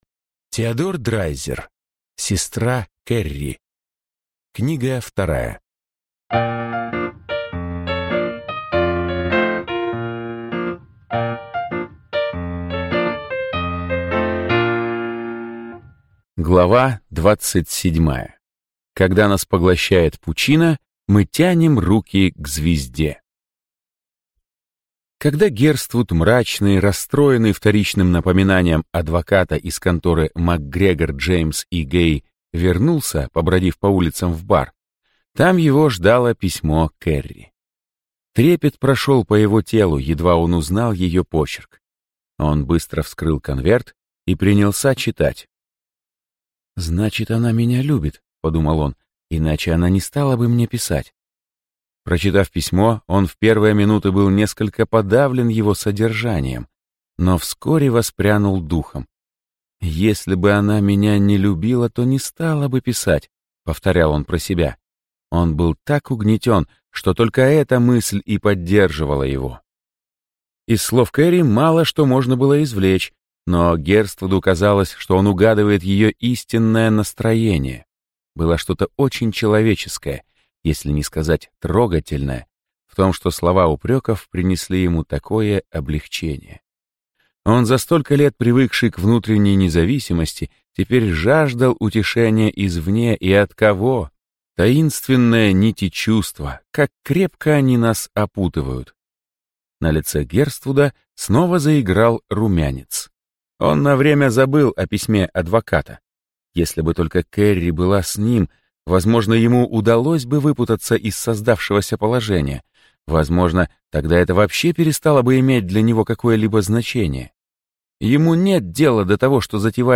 Аудиокнига Сестра Керри. Книга 2 | Библиотека аудиокниг